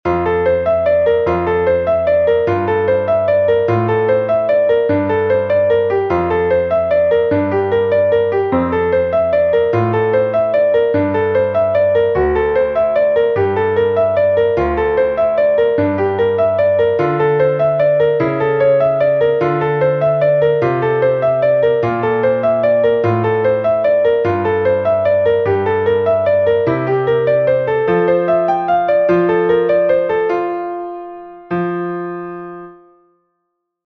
Entoación con acompañamento
Só acompañamento:
ENTONACION9UD3-Piano.mp3